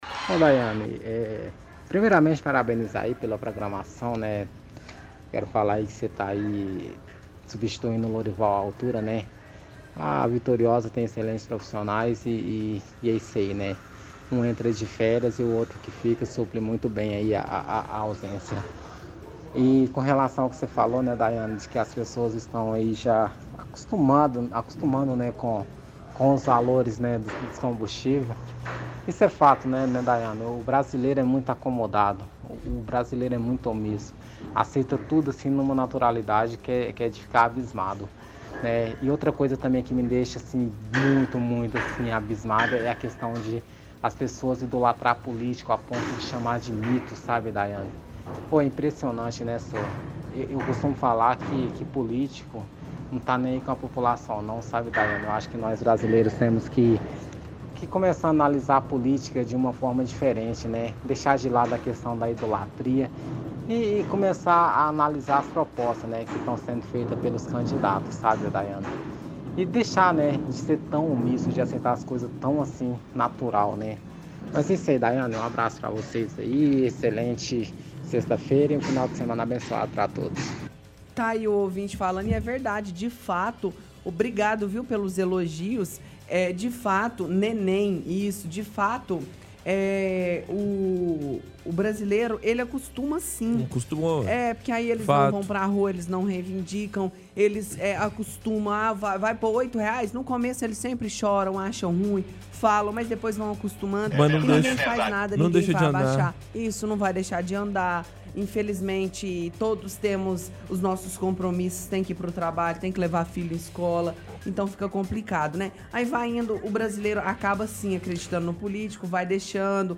– Apresentadores reclamam que a população acostumou com a frota reduzida do transporte público, que foi feita durante a pandemia, e agora convivem com os veículos lotados.